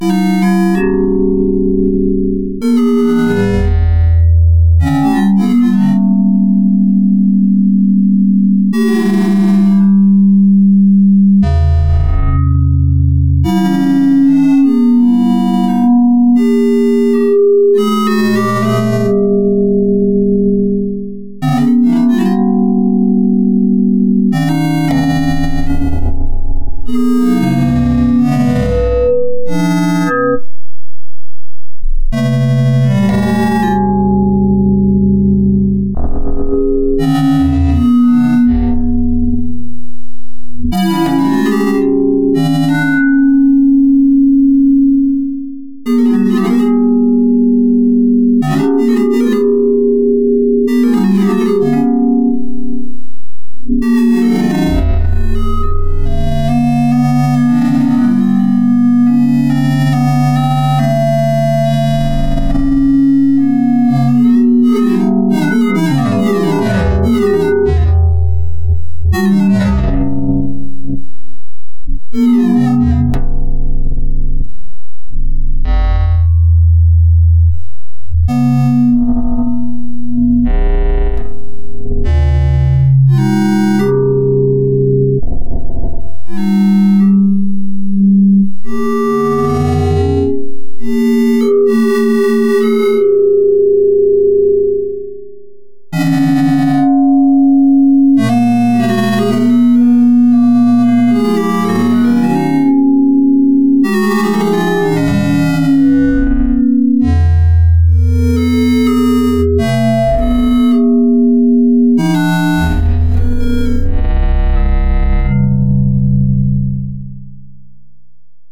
/////////// ...un oscilador a traves de un TGrains y un chingo de reverb!!!